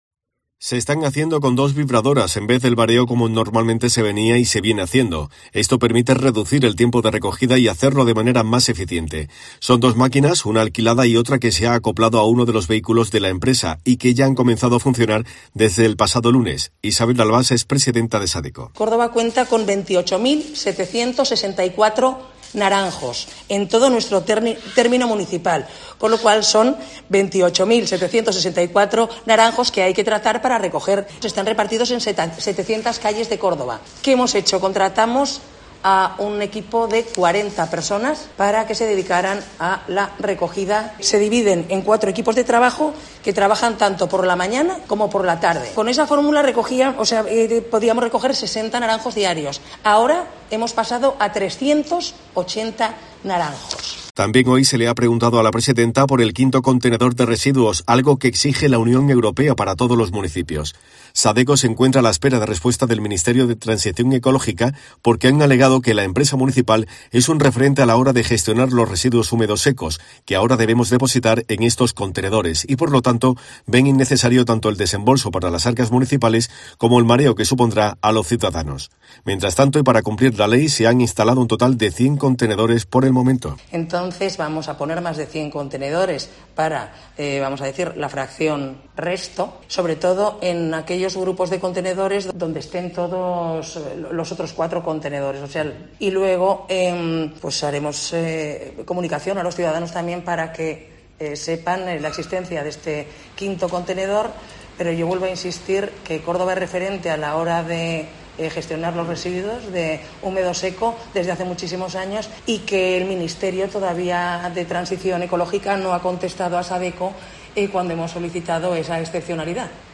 En una rueda de prensa, la concejal ha destacado que "el vibrador trata al árbol perfectamente y hace que las naranjas se caigan de una forma más fácil", de modo que "se ha convertido en una herramienta muy útil", que "se ha adaptado a uno de los vehículos de Sadeco", tras "la formación pertinente a los trabajadores", de modo que "con la antigua fórmula que se tenía, se recogían 60 naranjos diarios, y ahora se ha pasado a 380", ha resaltado.